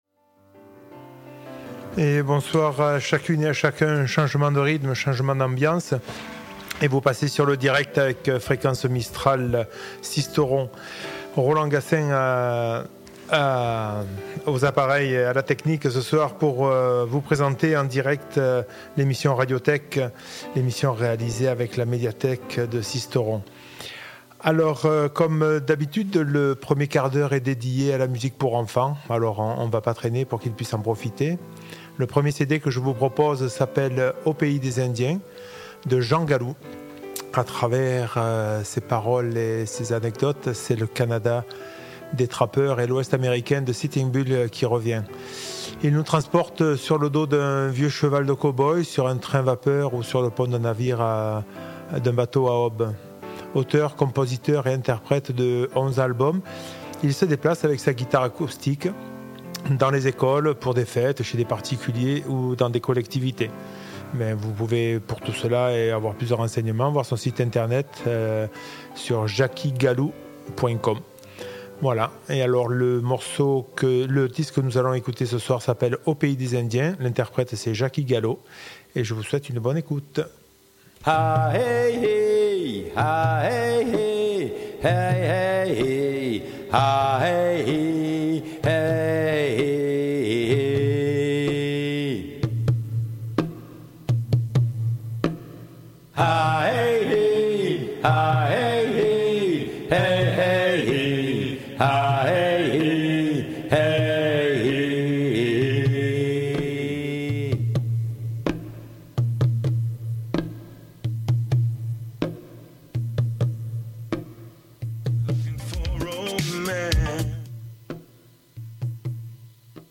Le tout agrémenté par de la chanson pour enfants en début de soirée, du jazz, du blues, de la bonne chanson française et du classique pour terminer l'émission en beauté.